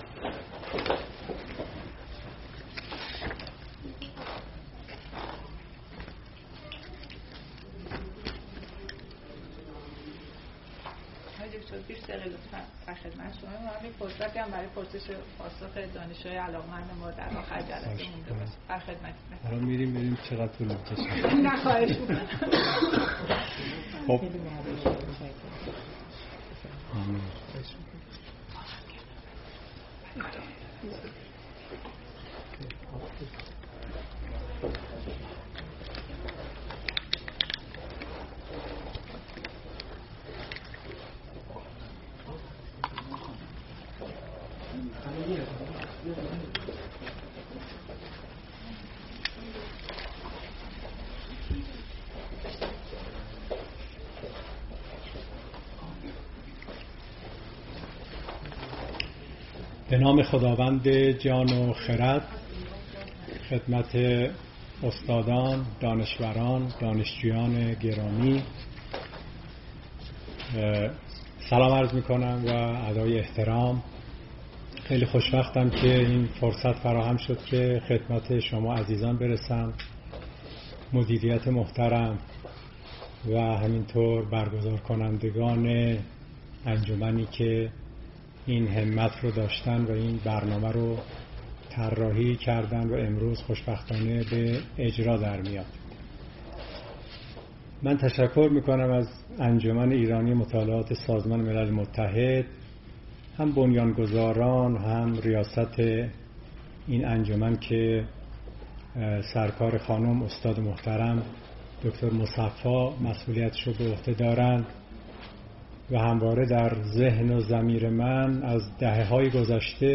نظریۀ سیاسی جان رالز X انجمن ایرانی مطالعات سازمان ملل متحد، با همکاری دانشگاه خوارزمی سخنران
خلاصۀ سخنرانی